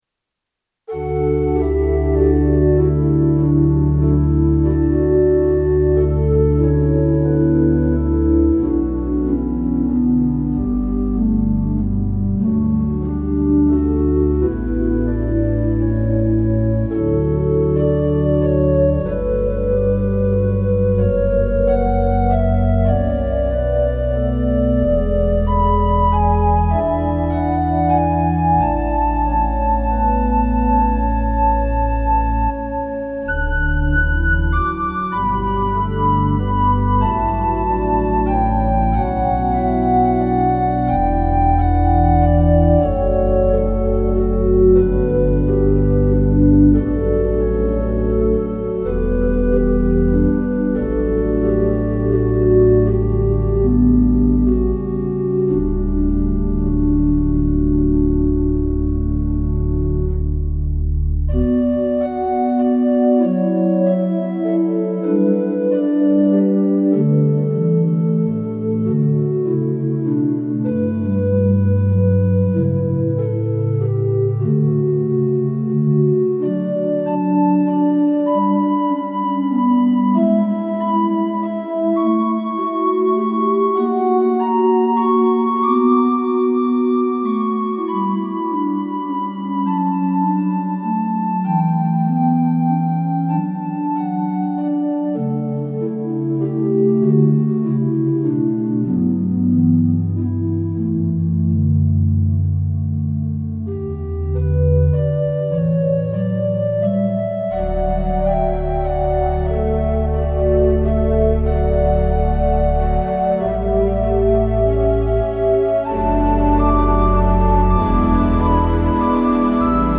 Rodgers 205 Hybrid Organ (circa 1978)